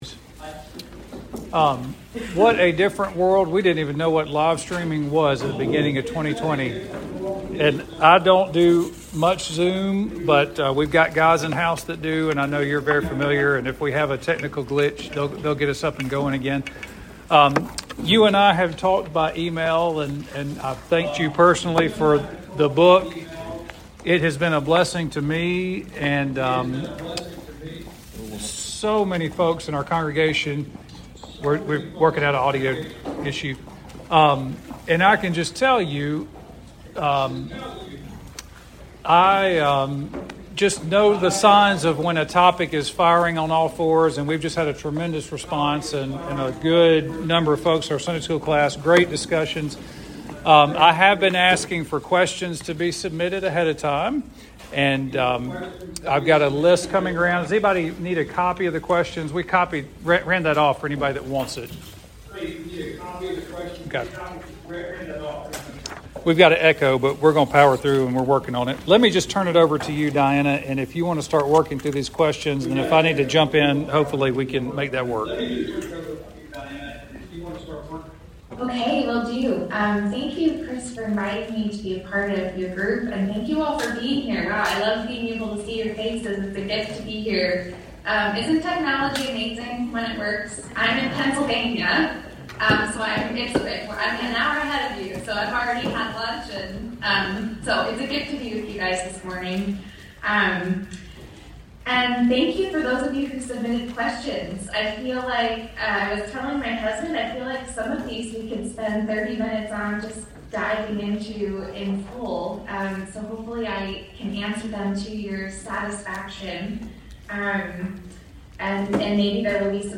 Companions in the Darkness Q&A - Trinity Presbyterian Church